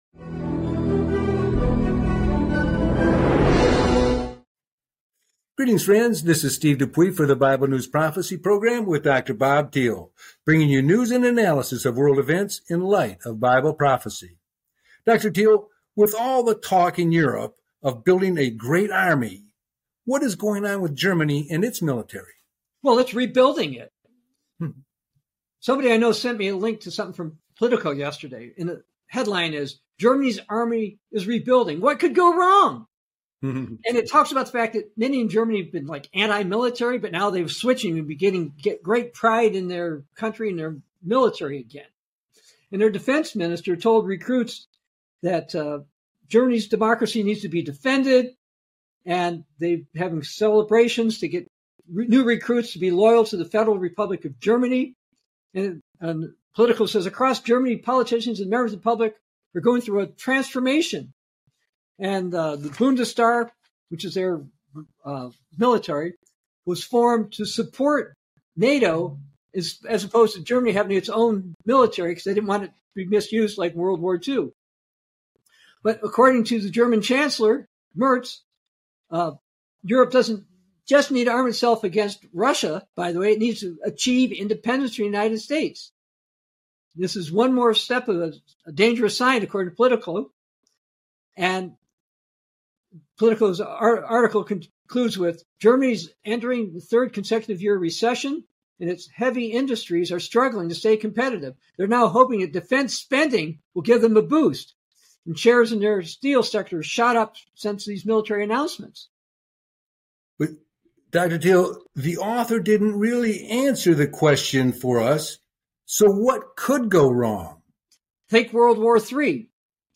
Talk Show Episode, Audio Podcast, Bible News Prophecy and Germany’s Great Army – Any Problems?